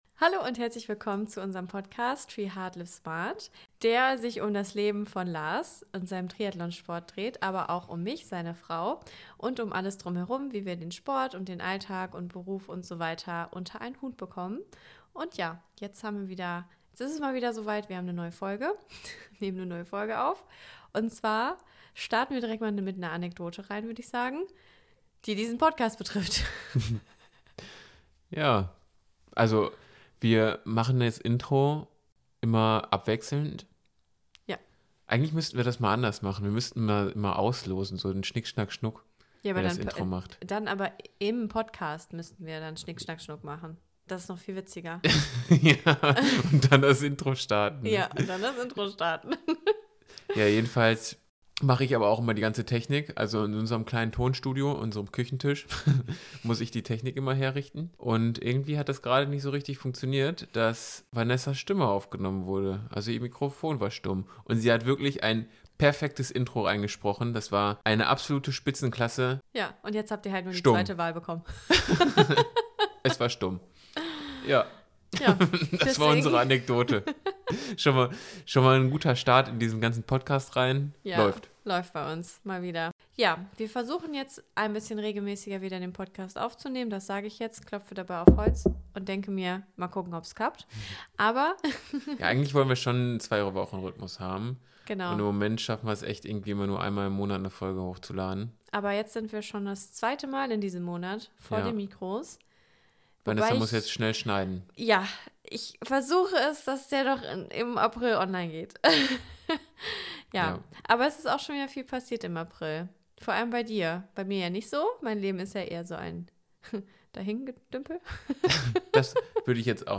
Es geht um das erste Rennen in diesem Jahr und unerwartete Fragen haben wir auch wieder im Gepäck. Aufgenommen haben wir die Episode nach einem langen Arbeitstag, dementsprechend wird viel gelacht und Quatsch erzählt.